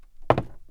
woodFootstep04.wav